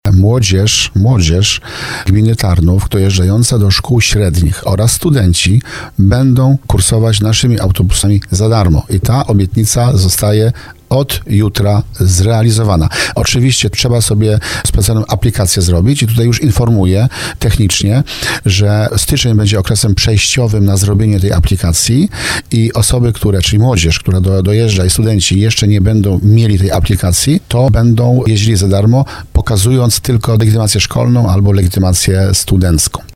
Wójt Grzegorz Kozioł, który był gościem programu Słowo za Słowo podkreślił, że to ważny krok i jednocześnie realizacja jego obietnicy wyborczej.